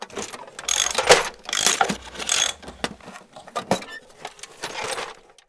repair.wav